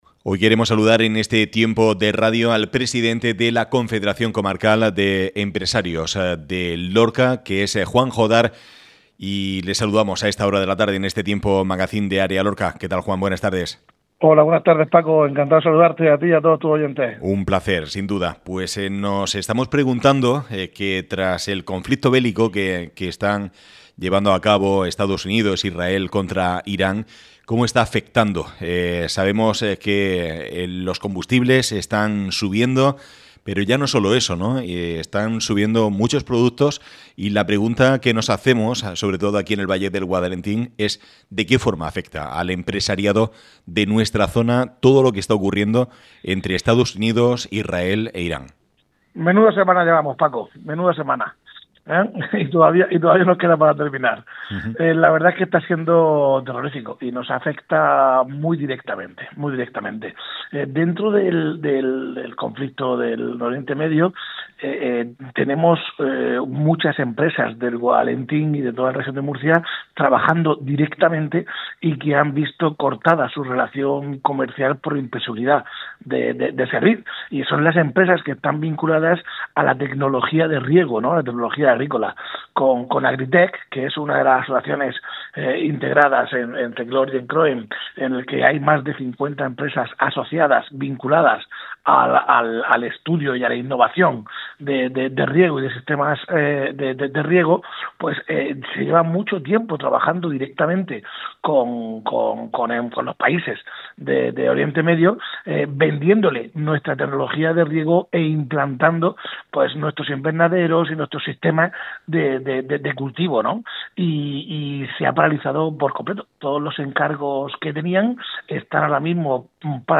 ÁREA LORCA RADIO.